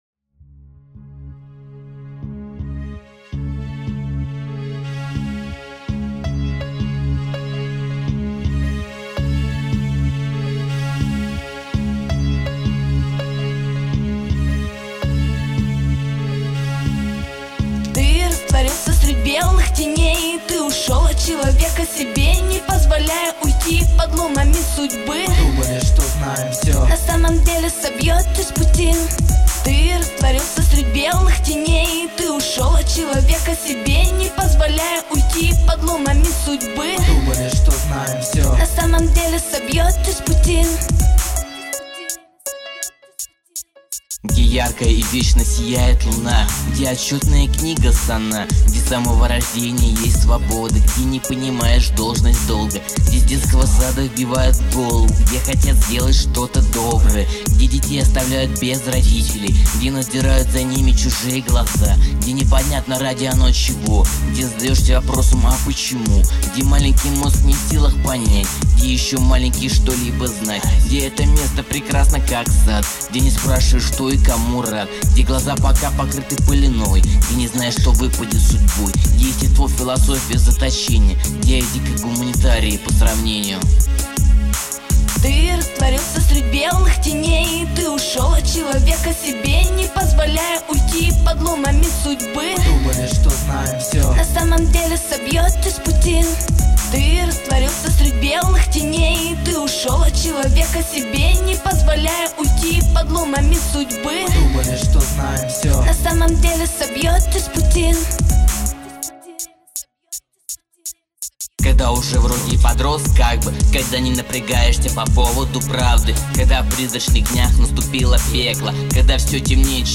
Чувствуется такой уличный пацанский рэп.
Голос у девушки супер